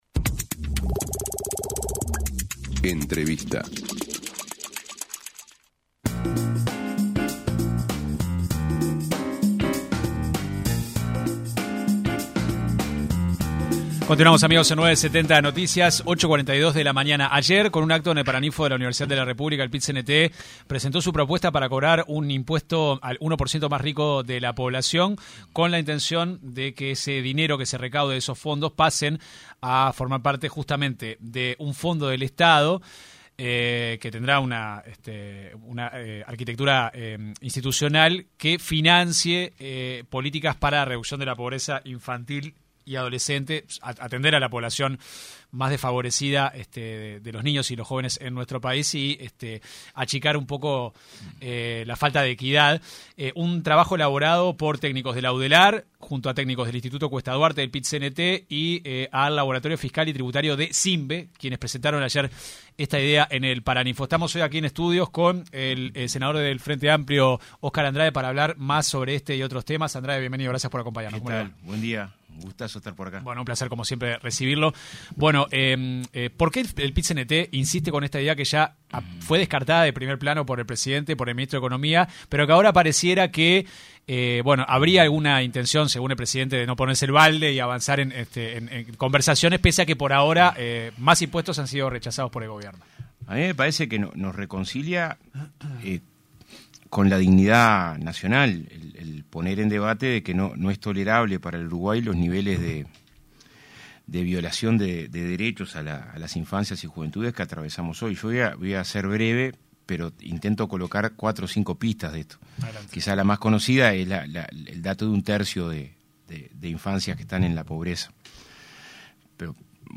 AUDIO El senador del Frente Amplio (FA), Oscar Andrade en entrevista con 970 Noticias explicó la propuesta del Pit-Cnt y de distintos legisladores frenteamplistas de aplicar una sobretasa al Impuesto al Patrimonio para el 1% más rico del país, con destino exclusivo a políticas contra la pobreza infantil.